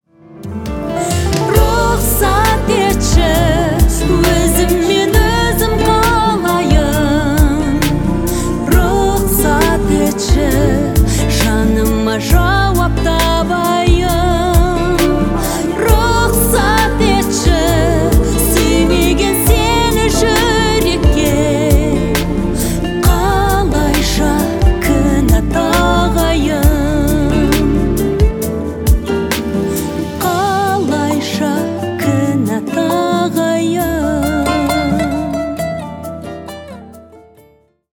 Поп Музыка
спокойные # грустные